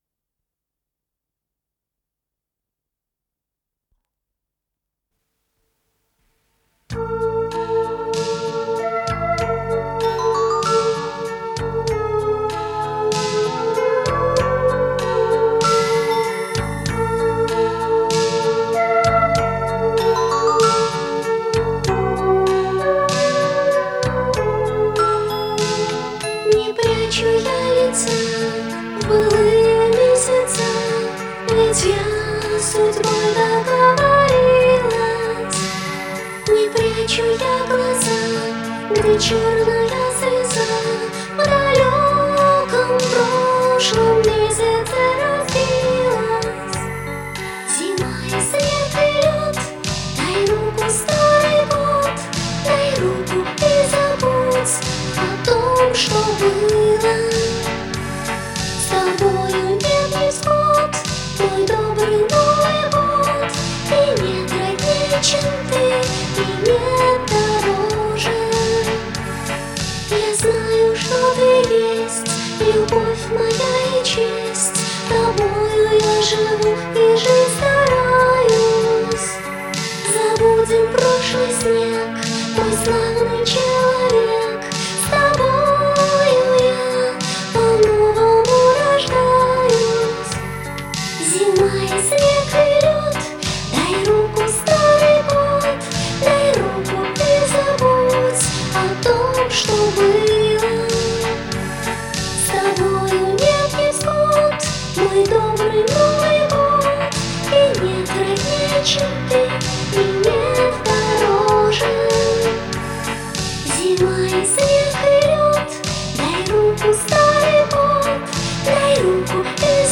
с профессиональной магнитной ленты
пение
АккомпаниментИнструментальный ансамбль